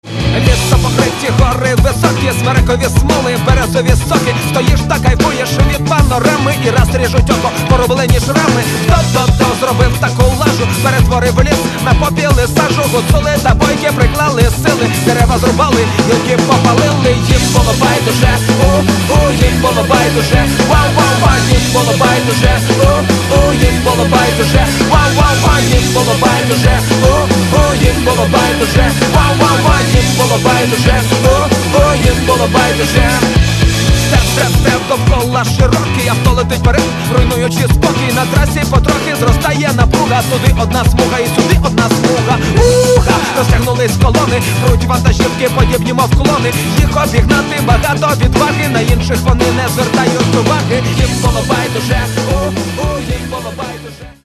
Catalogue -> Hip-Hop